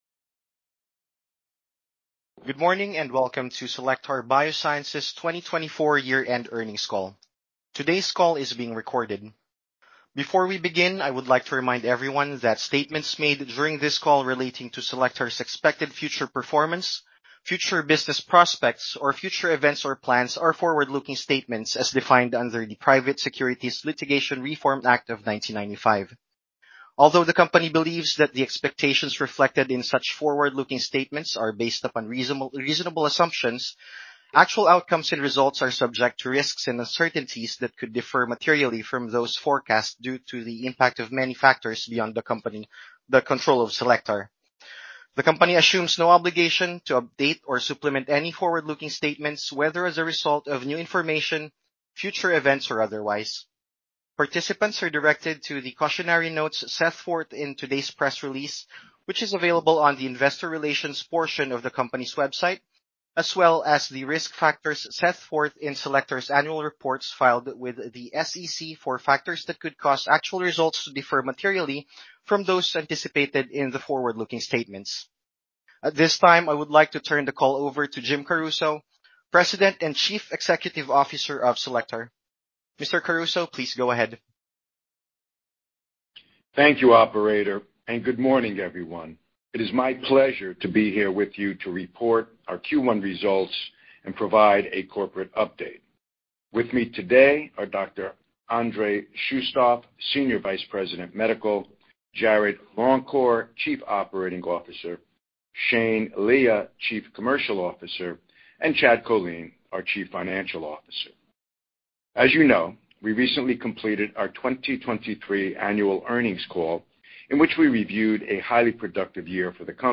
Conference Call Audio